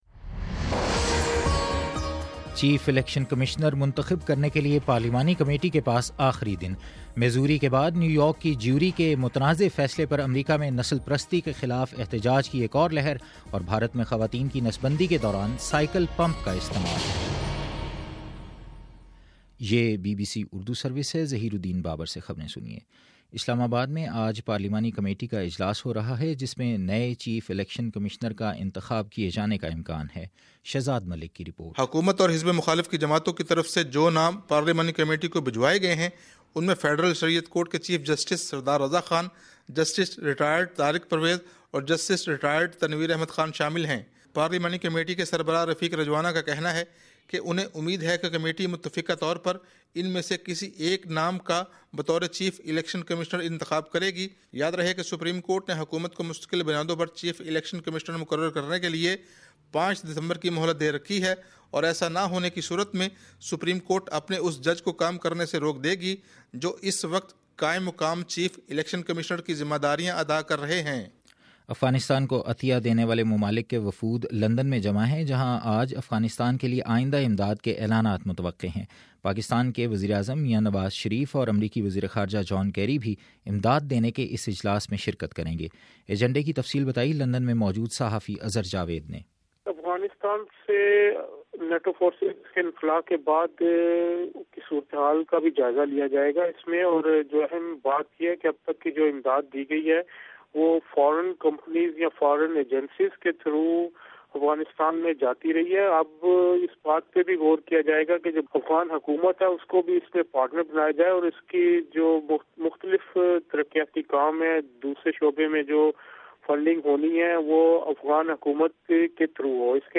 دسمبر04: : صبح نو بجے کا نیوز بُلیٹن